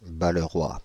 Balleroy (French pronunciation: [baləʁwa]
Fr-Balleroy.ogg.mp3